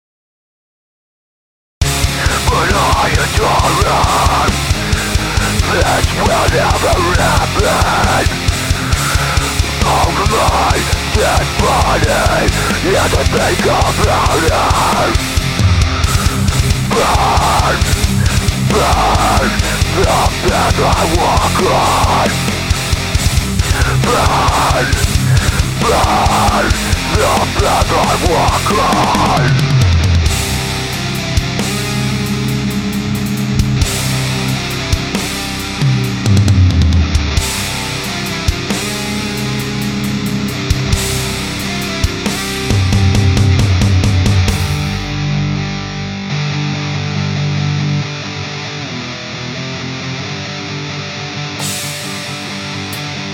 The only thing on this is alittle comp on drums, and a limiter on the master bus. no eq, no nothing. Also, this is to show you that you don't have to have alot of equipment to make a good recording, this is amp sims, ezdrummer, direct in bass track, and my own vocals.